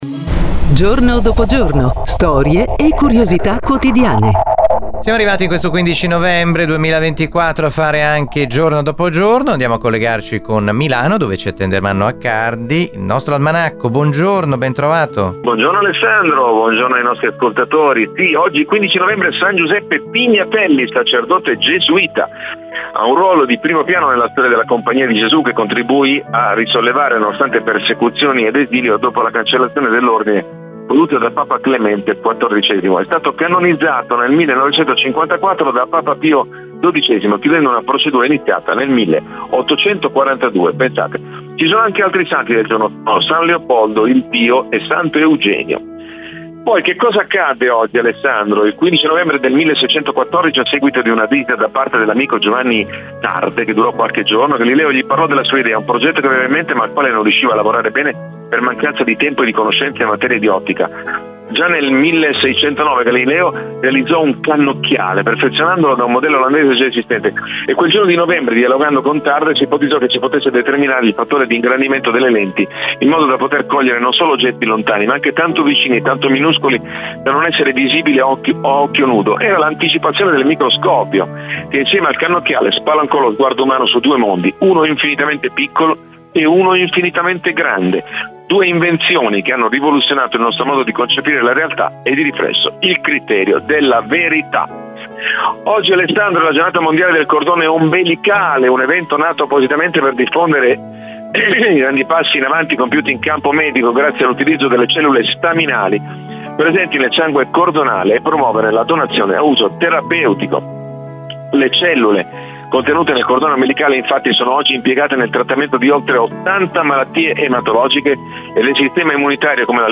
Le nostre produzioni hanno sigle di livello professionale e voci fuori campo nazionali e internazionali.